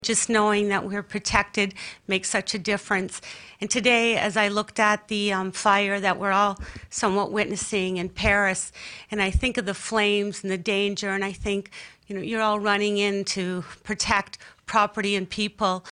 Councillor Karen Sharpe echoed the mayor’s sentiment and credited all of the award winners for their service to the community.